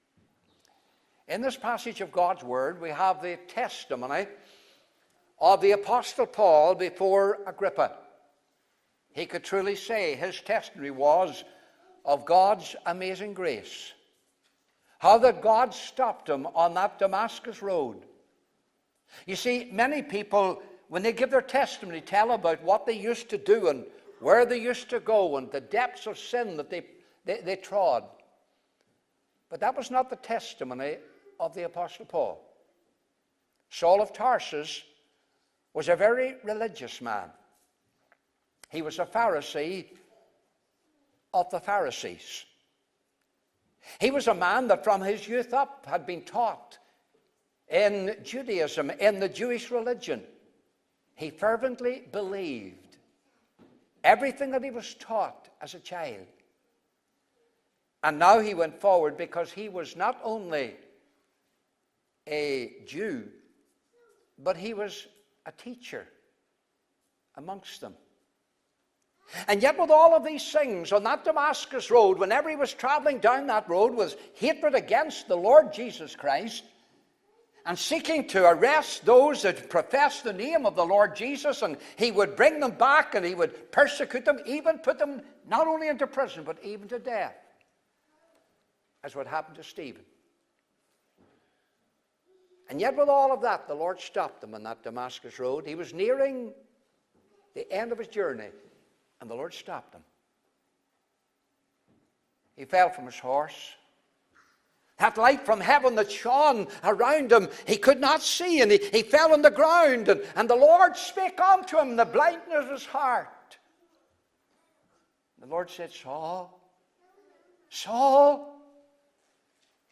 Rev. William McCrea | Coleraine Free Presbyterian Church